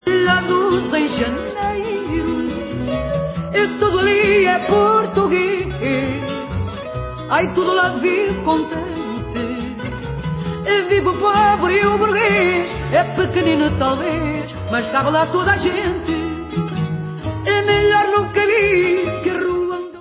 World/Fado